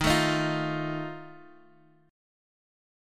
D#sus2b5 chord